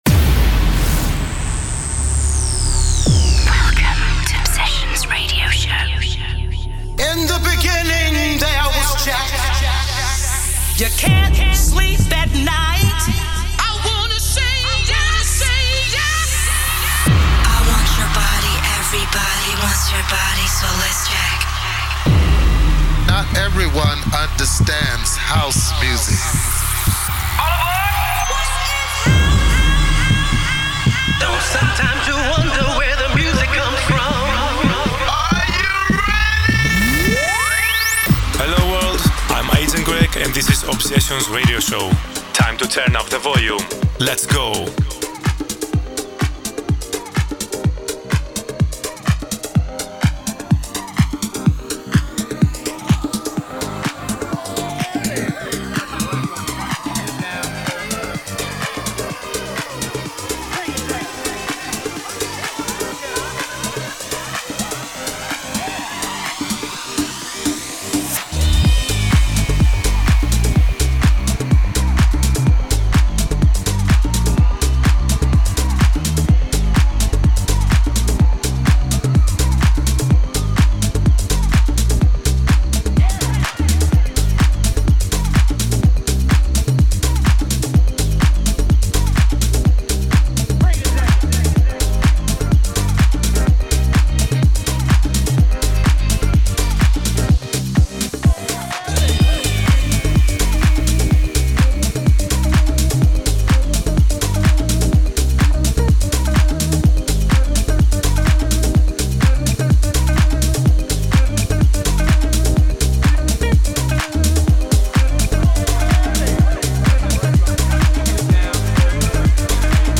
weekly 1 hour music mix
Expect nothing but pure House music.